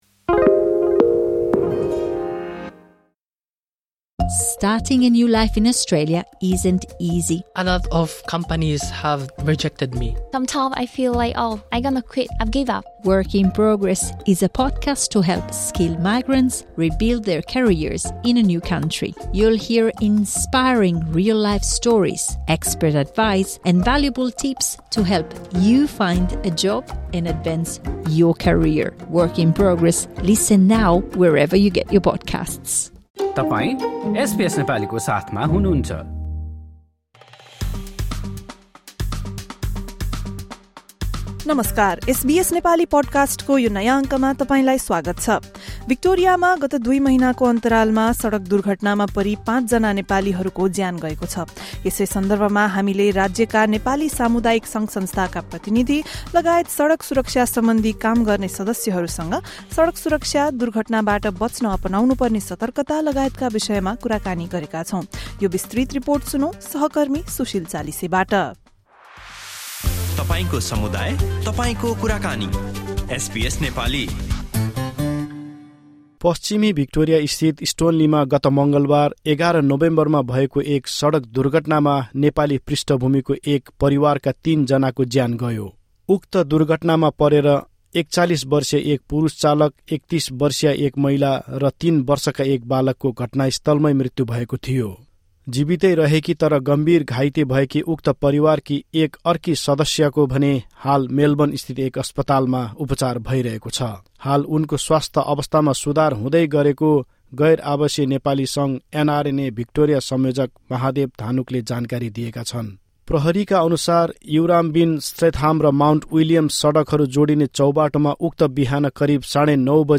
सडक सुरक्षा र दुर्घटनाबाट बच्न अपनाउनु पर्ने सतर्कता लगायतका विषयहरूमा गरेको कुराकानी समावेश गरी तयार पारेको रिपोर्ट सुन्नुहोस्।